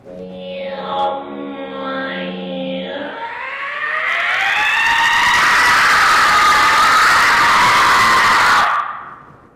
Doodlebob Screaming Sound Button - Free Download & Play